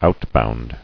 [out·bound]